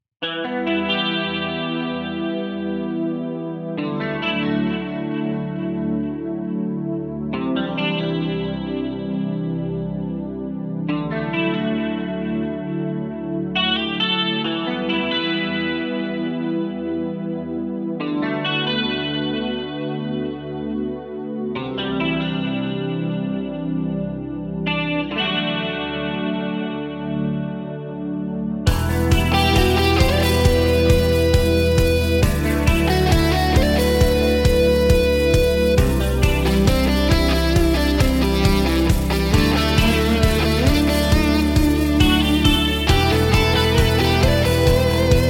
包括颈式和桥式拾音器，以原始 24 位质量同时录制。
• 6弦电吉他
标志性的音色，精心制作，非常适合放克、爵士、融合、环境等。
从干净、环境和低增益音调到过载、失真和高增益金属声音。